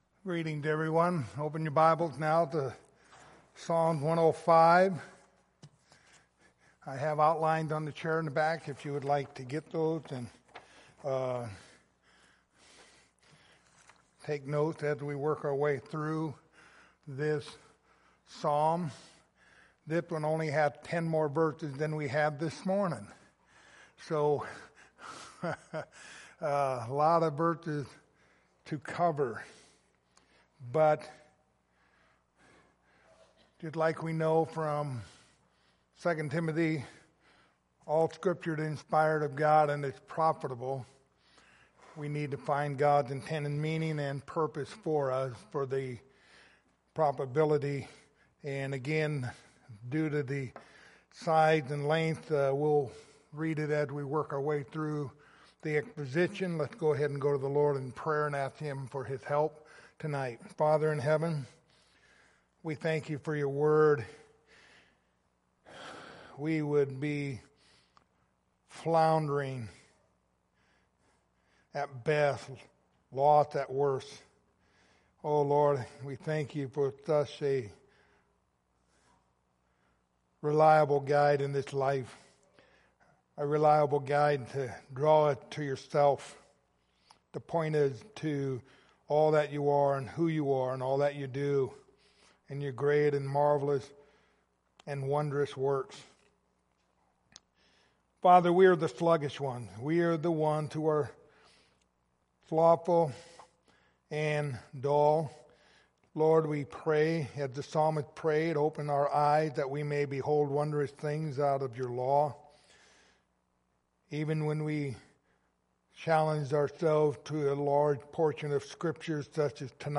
Passage: Psalms 105:1-45 Service Type: Sunday Evening